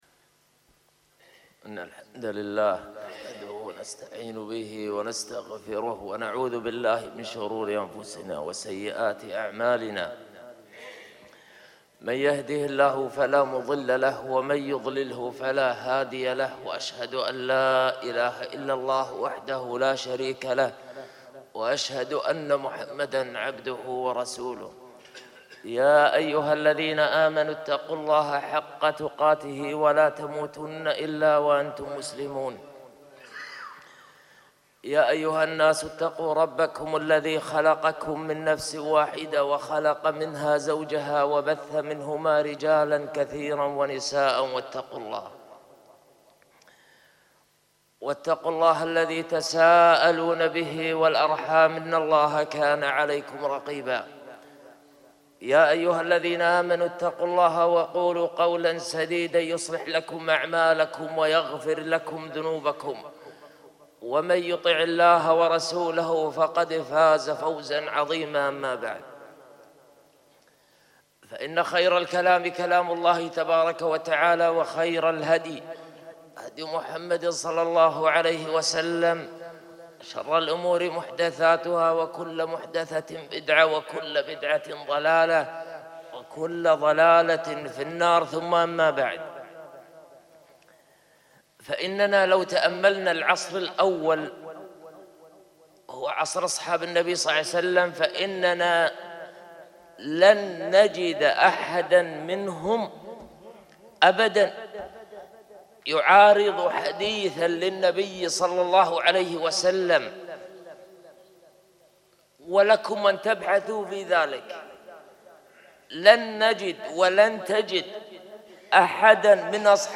خطــب الجمــعة